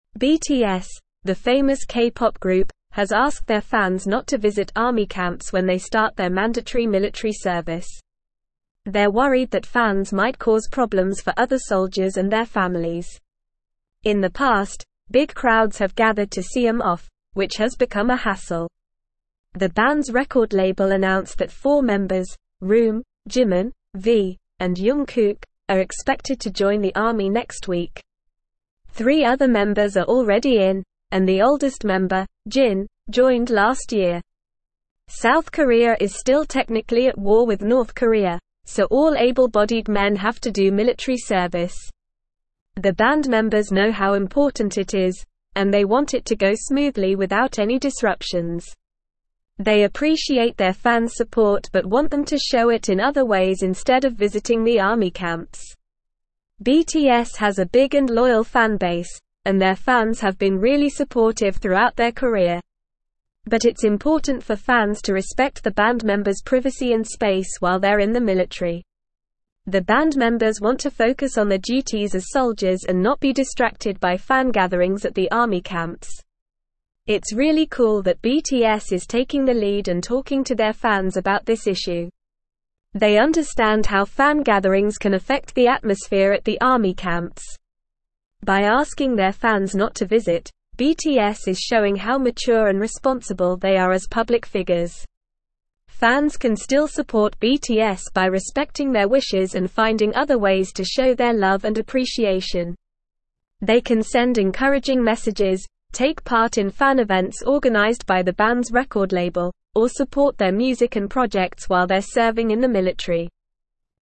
Normal
English-Newsroom-Upper-Intermediate-NORMAL-Reading-BTS-Urges-Fans-to-Stay-Away-from-Army-Camps.mp3